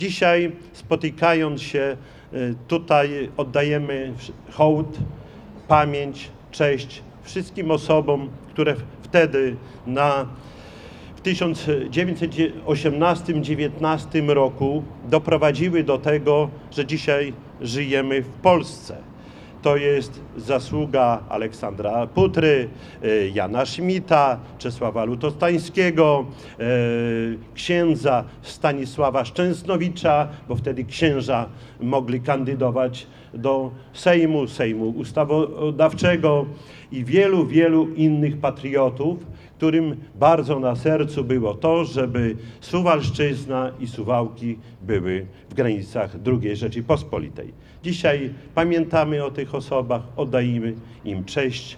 W piątek (24.08.18) mija 99 lat od wyzwolenia Suwałk. Z tej okazji w południe odbyły się okolicznościowe uroczystości. Przed pomnikiem Marszałka Józefa Piłsudskiego w centrum Suwałk spotkali się przedstawiciele samorządu, szkół i instytucji.
Z przemówieniem wystąpił Czesław Renkiewicz, prezydent miasta, który przywołał wydarzenia sprzed niespełna stu lat, kiedy po latach zaborów Suwalszczyzna odzyskała niepodległość.